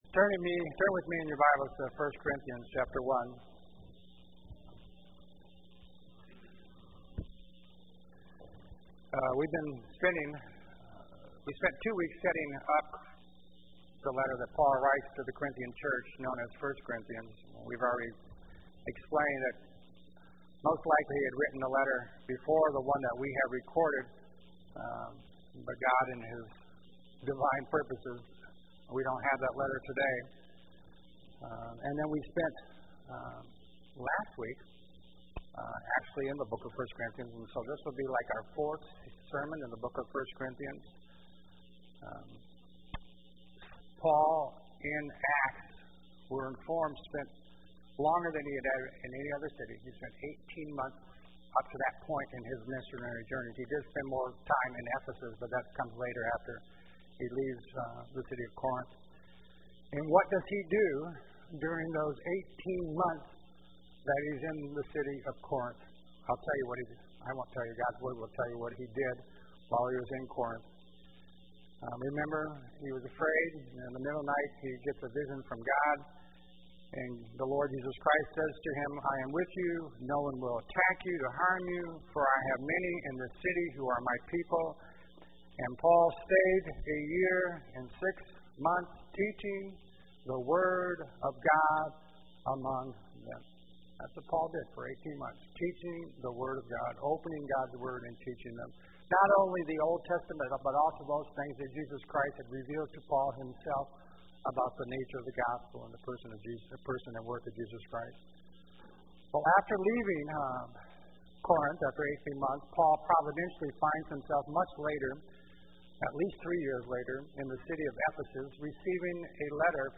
sermon_5-21-17.mp3